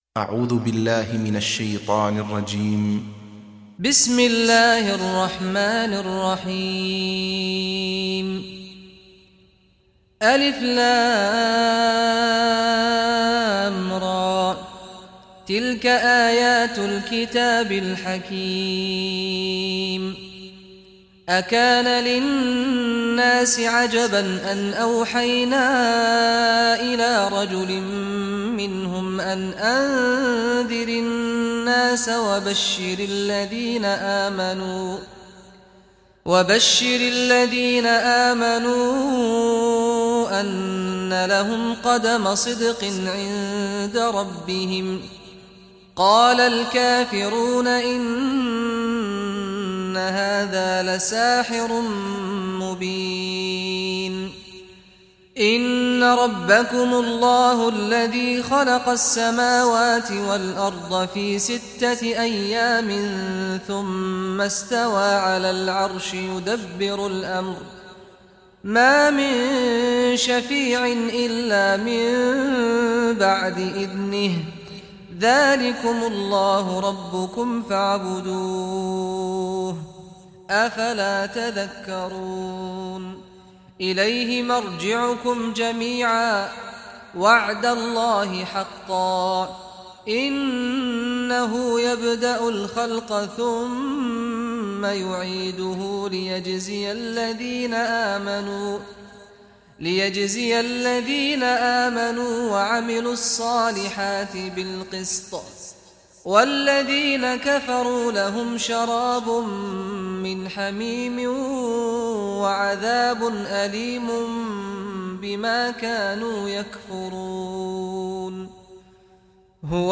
Surah Yunus Recitation by Sheikh Saad Ghamdi
Surah Yunus, listen or play online mp3 tilawat / recitation in Arabic in the beautiful voice of Imam Sheikh Saad Al Ghamdi.